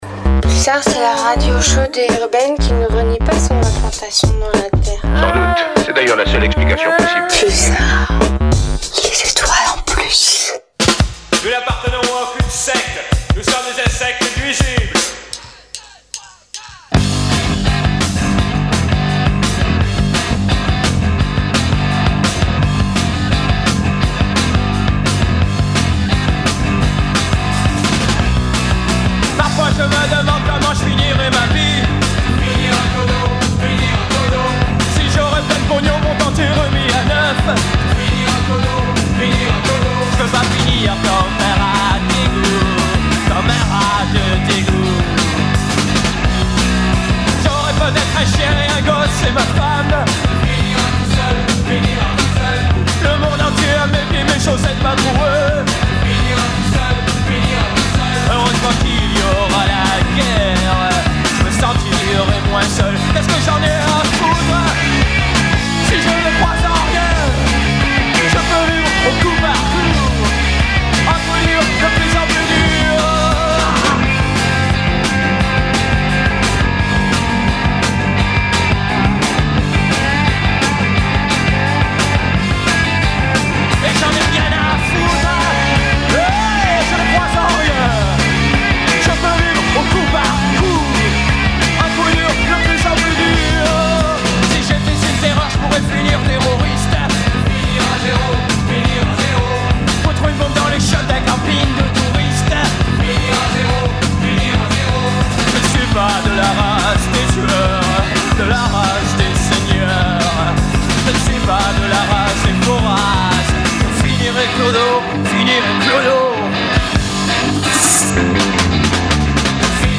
INTERVIEW
Au programme vagues souvenirs de guerre d’un punk et activiste poitevin, musique rock n roll, hardcore et échanges variés.